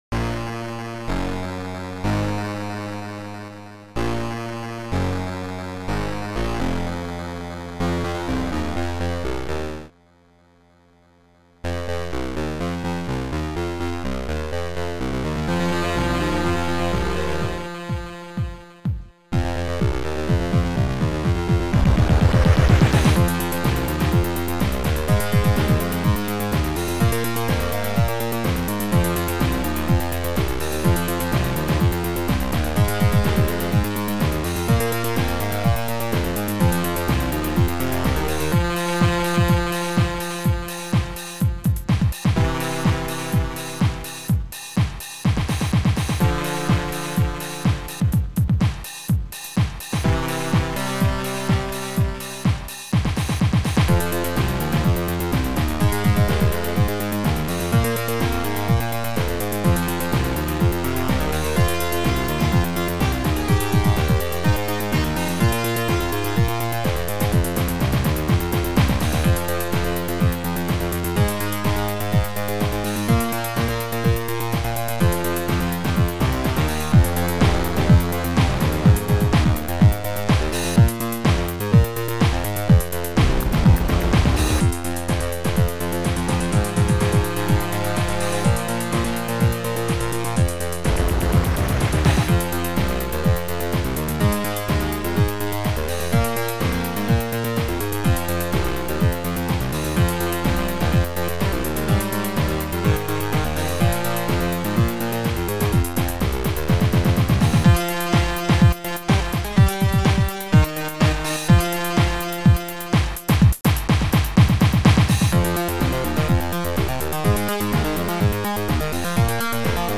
SidMon II Module